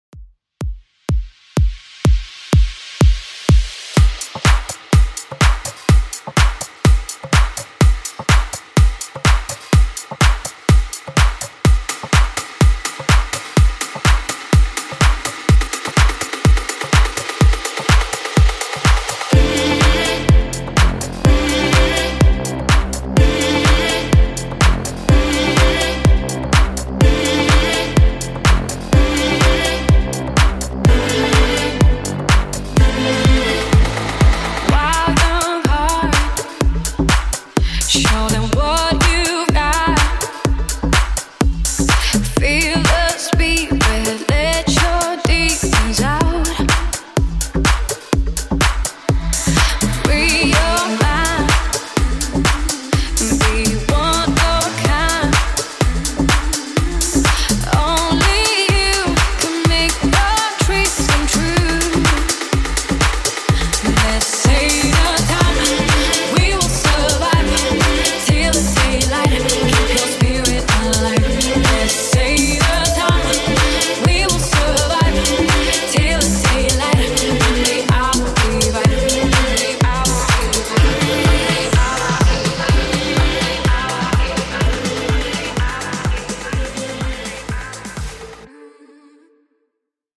DANCE , TOP40